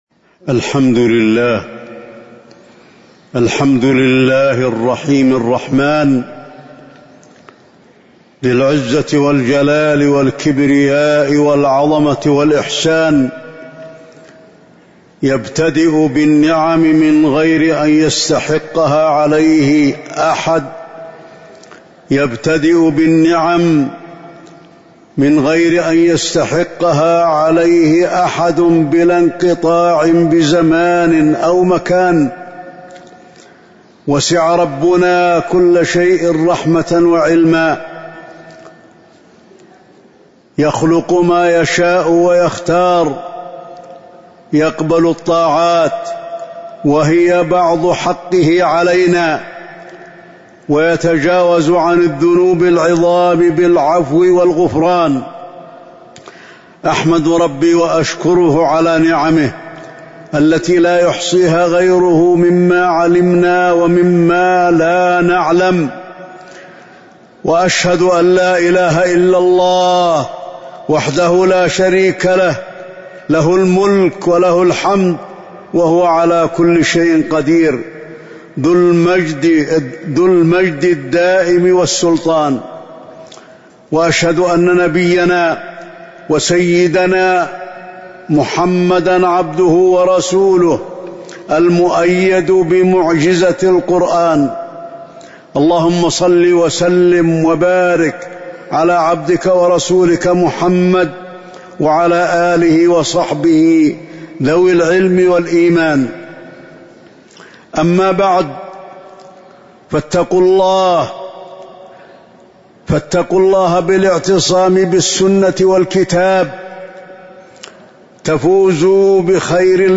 تاريخ النشر ٩ ذو الحجة ١٤٤٣ هـ المكان: المسجد النبوي الشيخ: فضيلة الشيخ د. علي بن عبدالرحمن الحذيفي فضيلة الشيخ د. علي بن عبدالرحمن الحذيفي يوم عرفة